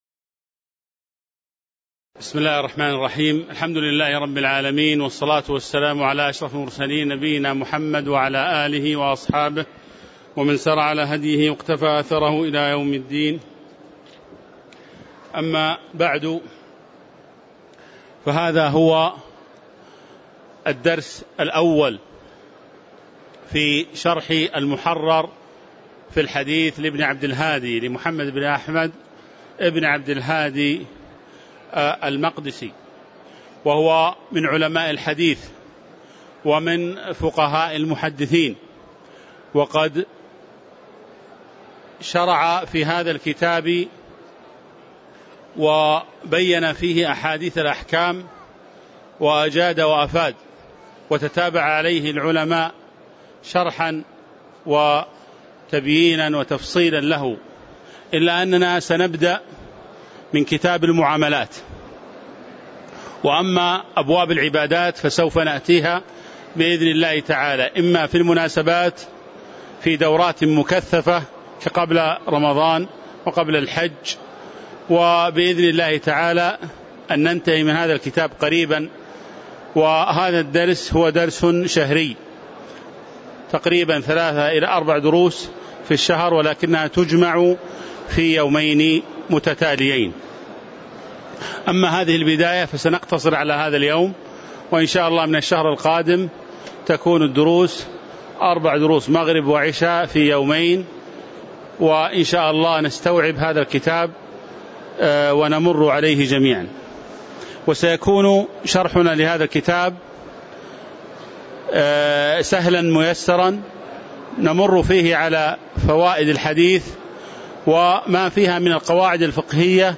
تاريخ النشر ٦ ربيع الثاني ١٤٤٦ هـ المكان: المسجد النبوي الشيخ